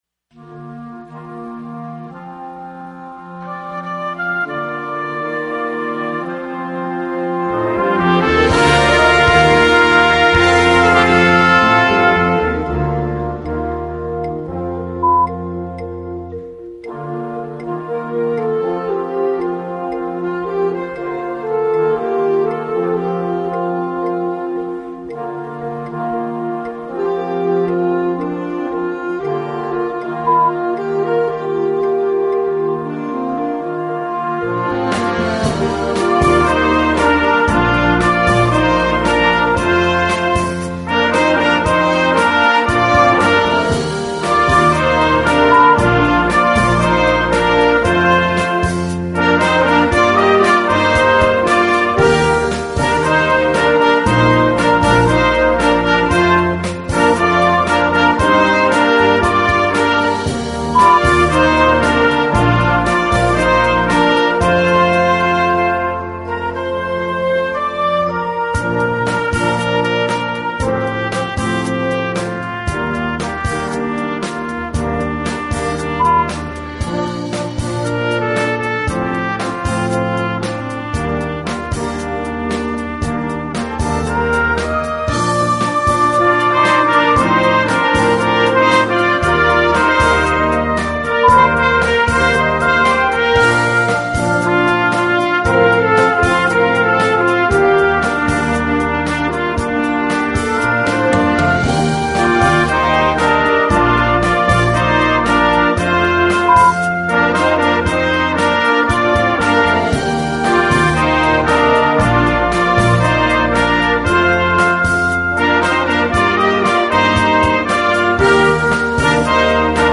Gattung: für Jugendblasorchester
Besetzung: Blasorchester